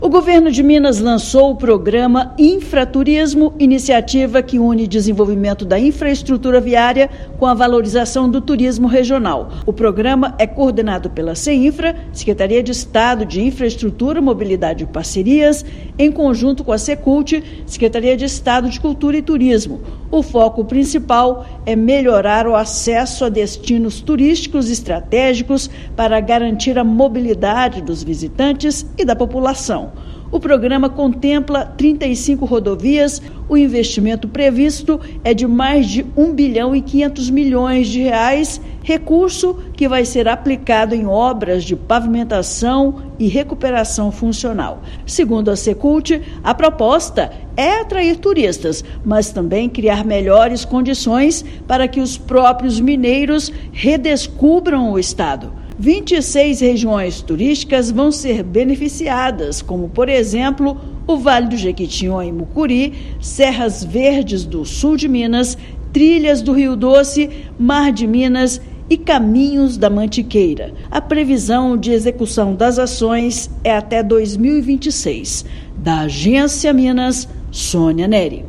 Com o Infraturismo, secretarias de Cultura e Turismo e de Infraestrutura e o DER-MG se unem para fortalecer destinos turísticos com mais de 1,3 mil quilômetros de obras em rodovias estratégicas. Ouça matéria de rádio.